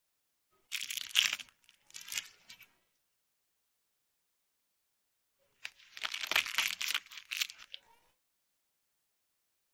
Tiếng Xé túi mù, Xé bao… (Ngắn)
Tiếng Xé túi mù, Mở bao ni lông… Tiếng Đổ những Đồ vật nhỏ ra khỏi bao
Thể loại: Tiếng động
Description: Tiếng xé túi mù, xé bao vang lên rõ rệt với âm thanh rọc rẹc, sột soạt, xoạt xoạt, tạo cảm giác chân thực như đang trực tiếp xé lớp bao bì. Hiệu ứng âm thanh này thường được dùng chỉnh sửa video, lồng ghép cảnh mở hộp, bóc tem, xé nhãn… giúp tăng tính sống động và thu hút người xem.
tieng-xe-tui-mu-xe-bao-ngan-www_tiengdong_com.mp3